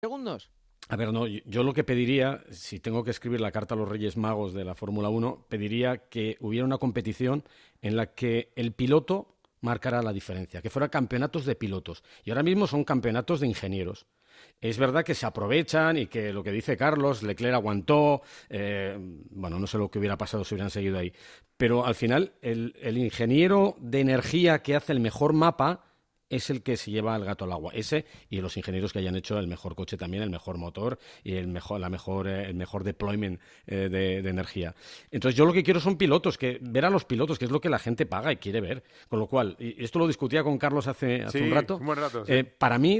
En este contexto, el periodista Antonio Lobato ha expresado una contundente opinión en la sección 'El Souffle' de 'El Partidazo de COPE', donde pide un cambio de rumbo para la competición.